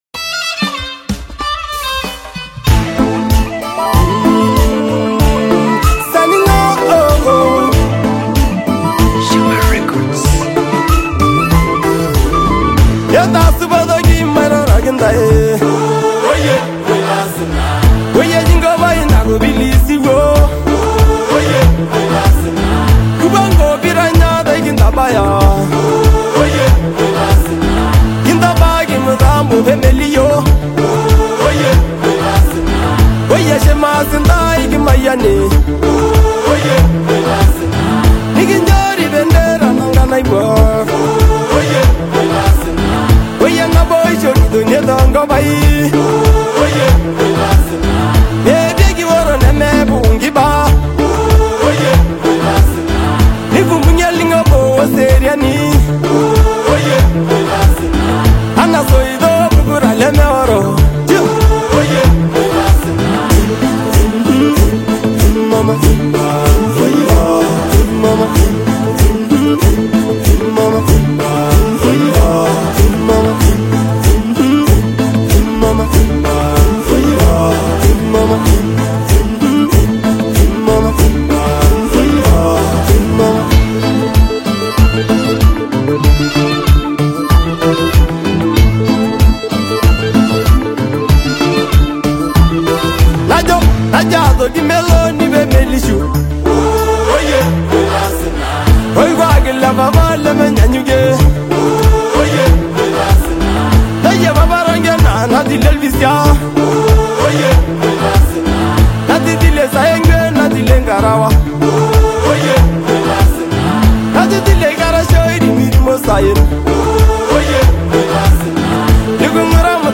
signature smooth vocals
blends elements of Afrobeat, R&B, and world music